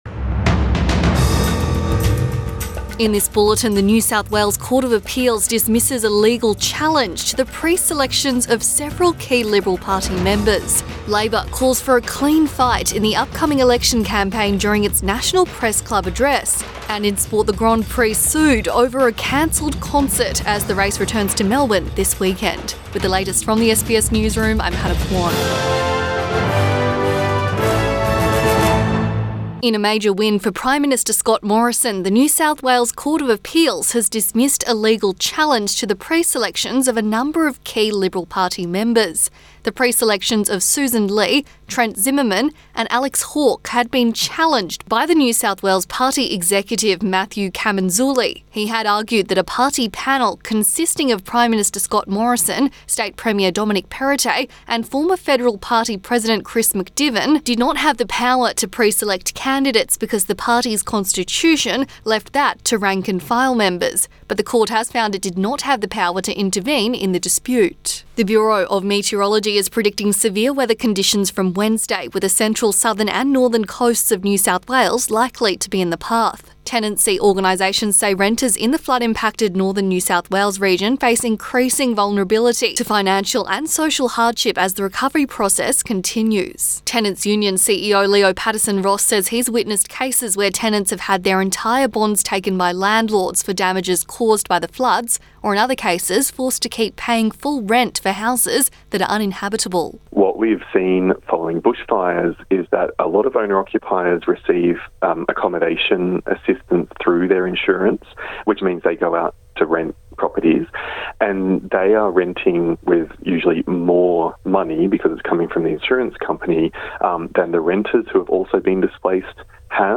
PM bulletin 5 April 2022